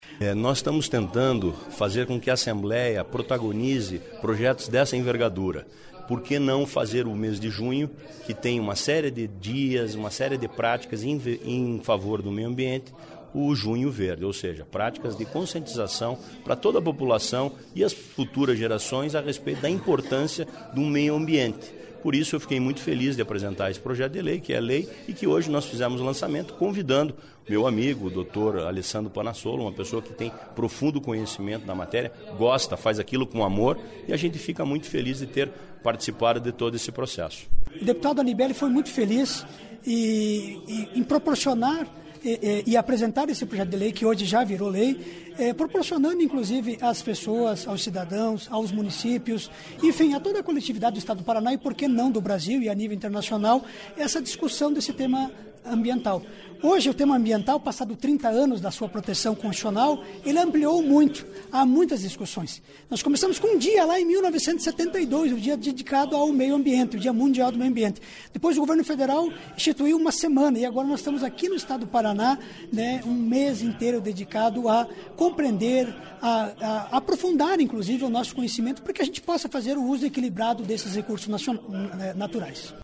Ouça as entrevistas com o parlamentar e com o professor.